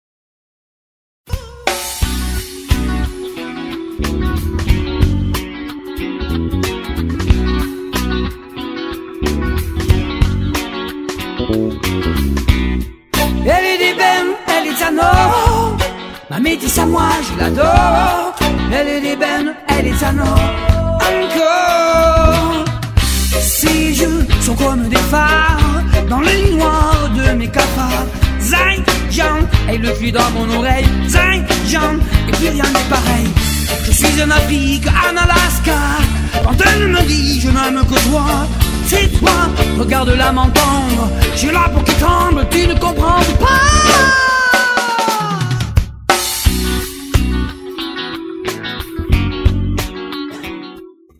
batterie
basse
claviers
chants